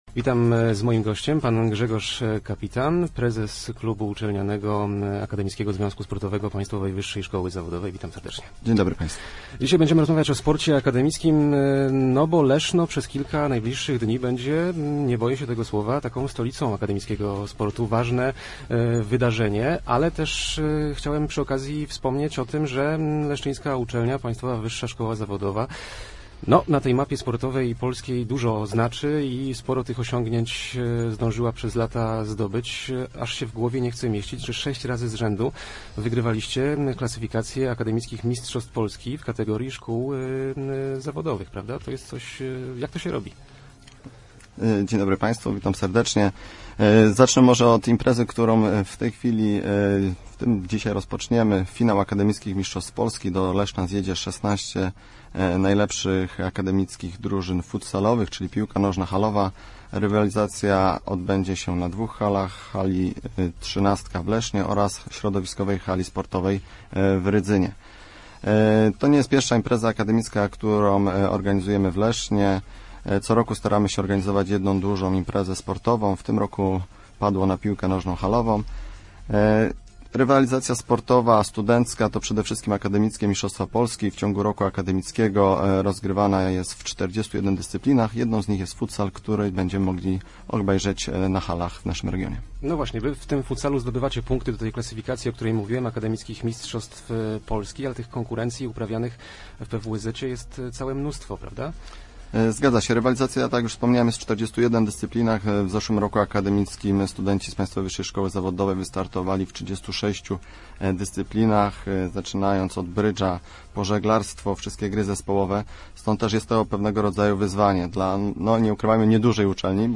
Start arrow Rozmowy Elki arrow Akademicki futsal w Lesznie i Rydzynie